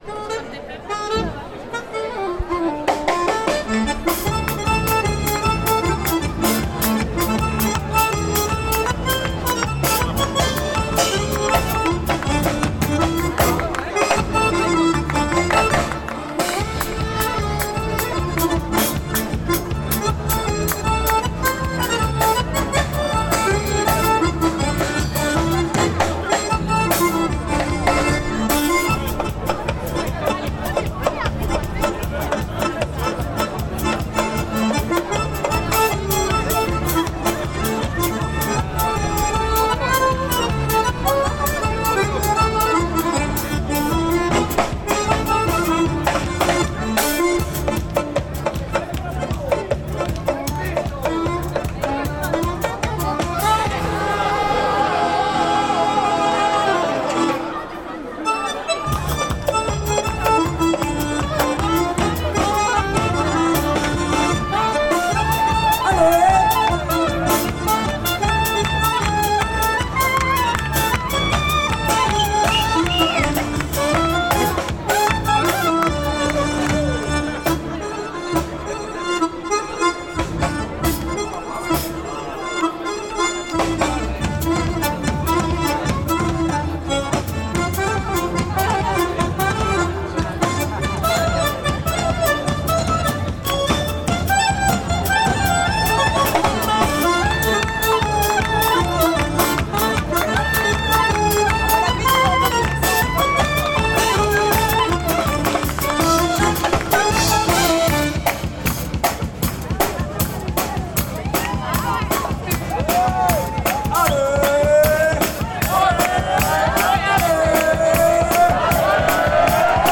Enregistrement musical de la fanfare
Abbaye de Floreffe 25·26·27 juillet 2025 Village des possibles Radio Radio Esperanzah!